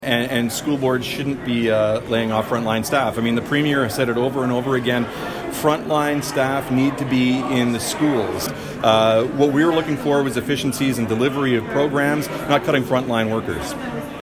Smith did take a few minutes to speak to media regarding the protest.